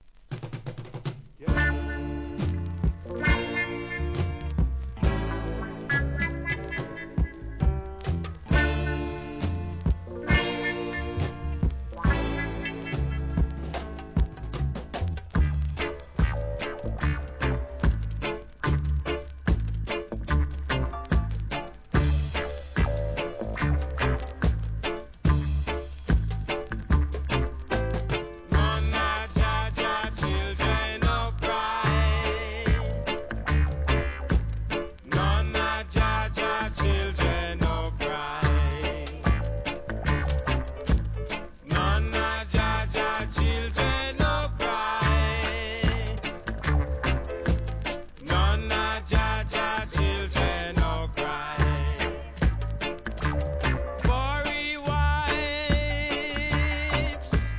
REGGAE
1975年ナイヤビンギの名作!!